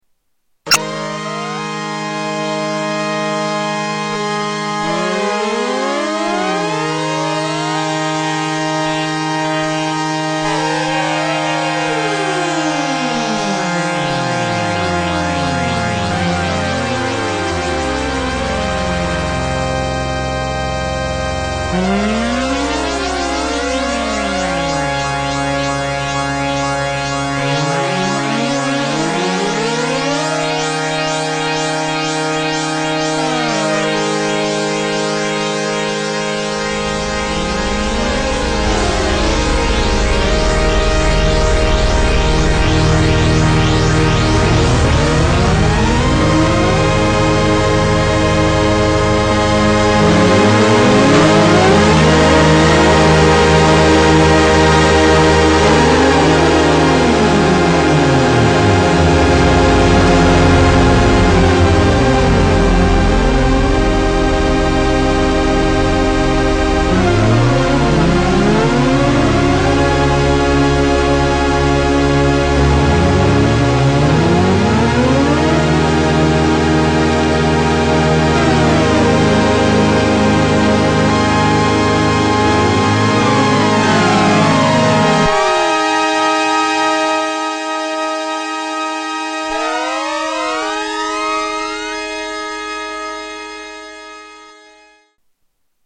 Jomox Sunsyn test 1
Tags: Sound Effects JoMoX Sounds JoMoX XBase AirBase